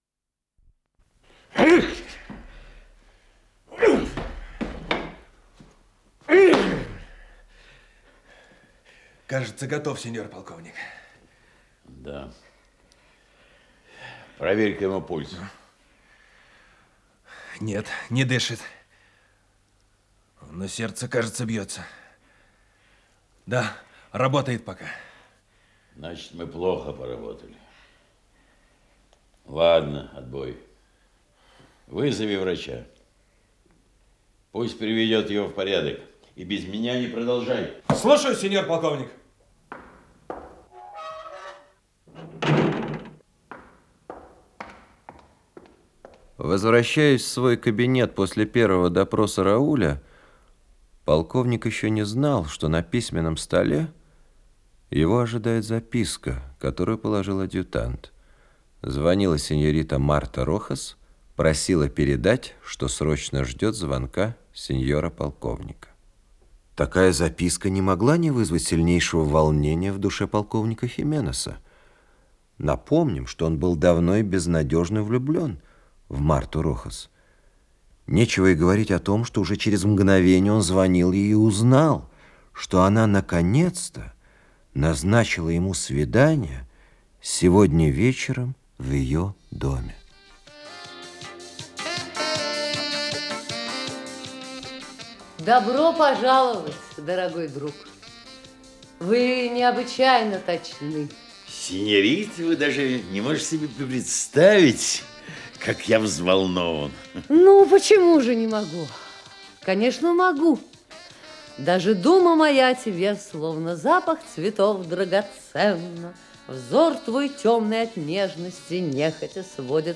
Исполнитель: Артисты московских театров
Радиотеатр политической пьесы, часть 2-я